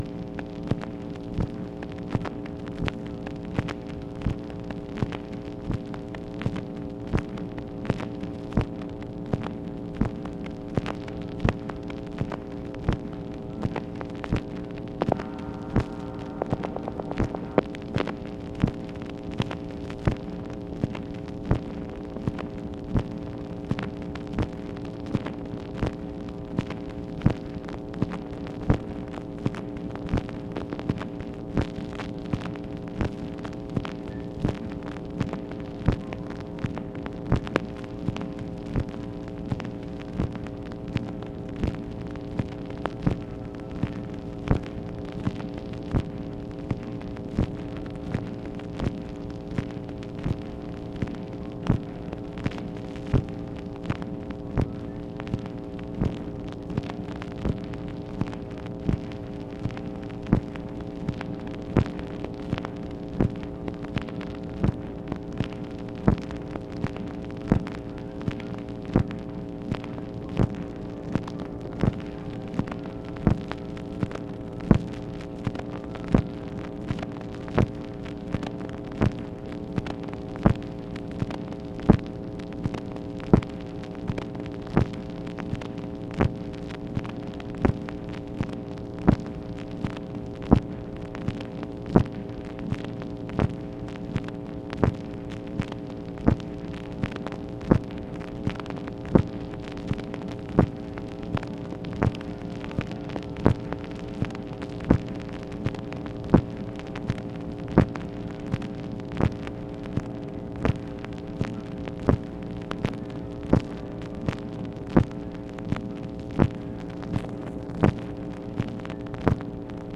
OFFICE NOISE, August 25, 1964
Secret White House Tapes | Lyndon B. Johnson Presidency